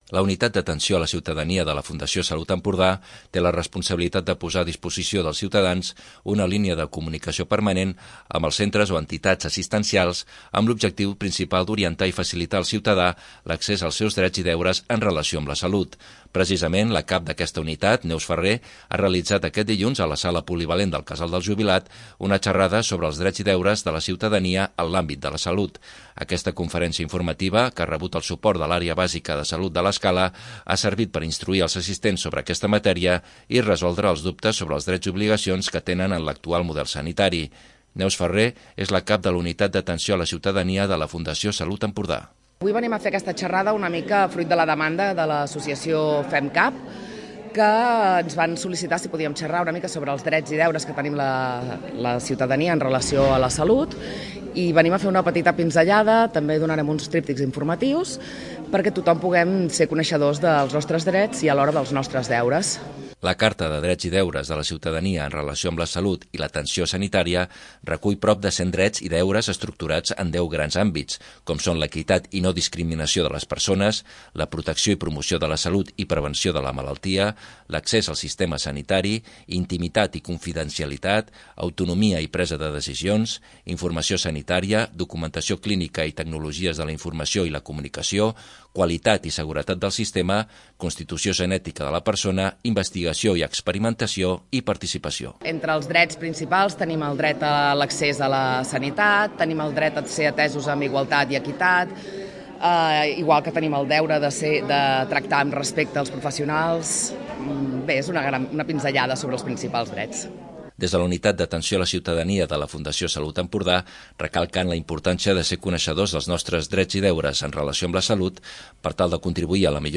Xerrada, a càrrec de la UAC, sobre drets i deures de la ciutadania en l'àmbit de la salut
A la Sala Polivalent del Casal del Jubilat s'ha celebrat una xerrada sobre drets i deures de la ciutadania en l'àmbit de la salut. L'objectiu de la conferència, que ha estat a càrrec de la Fundació Salut Empordà, ha sigut donar a conèixer als assistents els seus drets i obligacions en relació amb l'actual sistema sanitari.